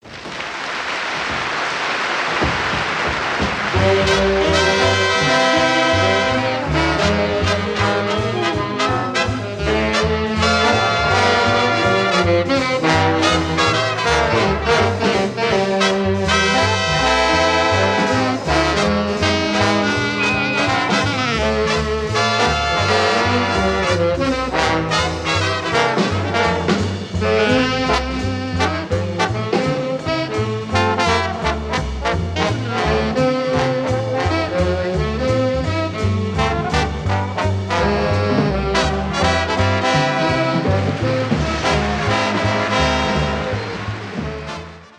Helsinki 1963